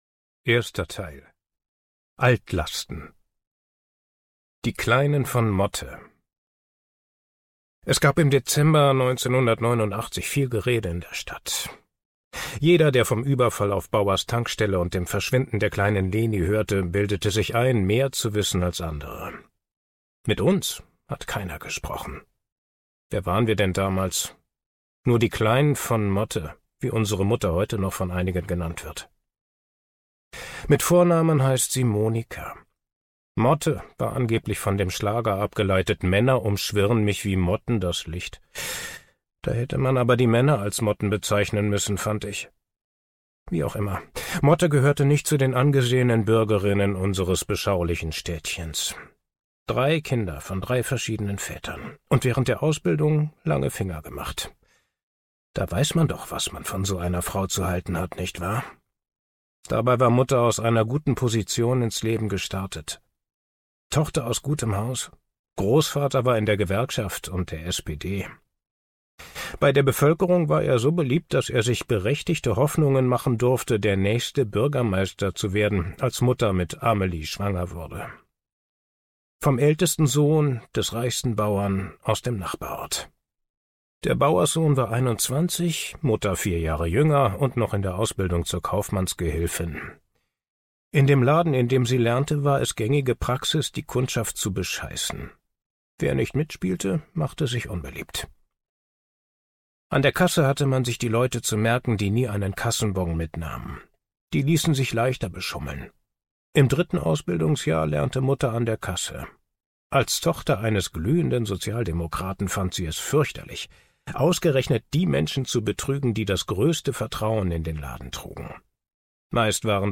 steinbach sprechende bücher | Hörbücher
Genre: Krimi & Thriller
Produktionsart: ungekürzt